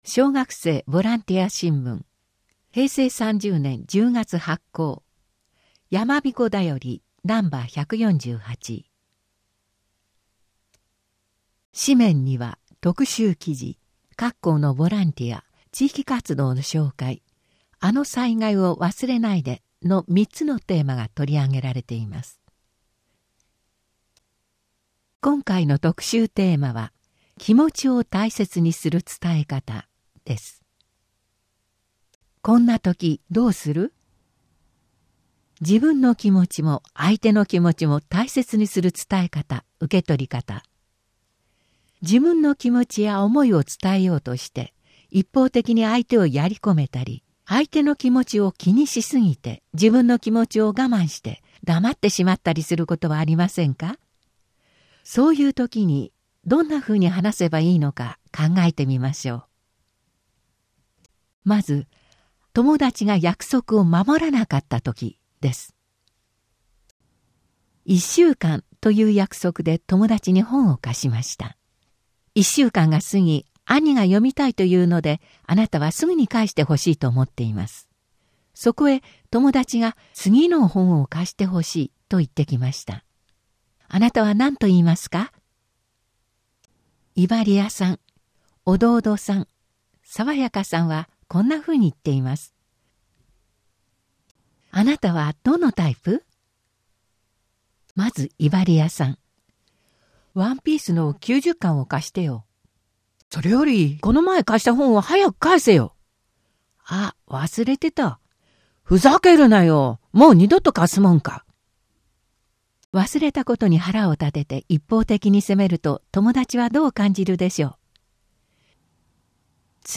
あわせて紙面の音訳データもご活用ください。